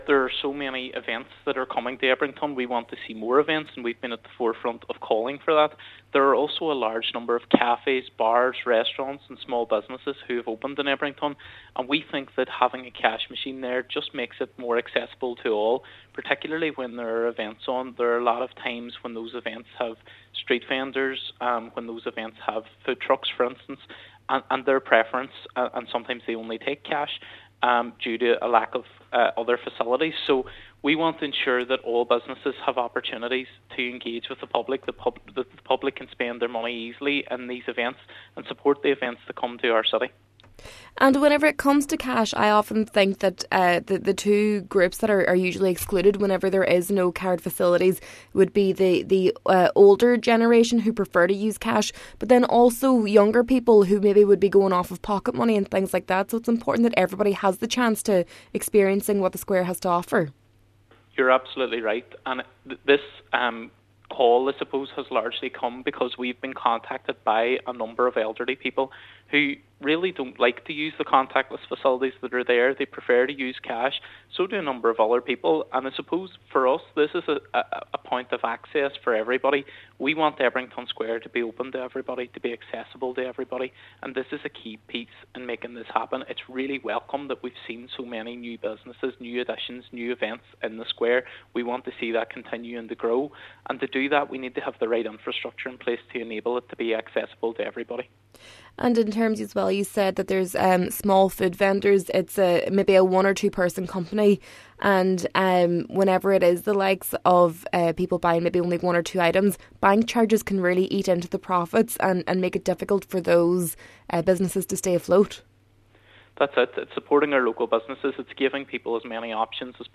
MLA Padraig Delargy said it’s vital that small businesses are supported, and that older people, in particular, are not disadvantaged by their preference for using physical cash: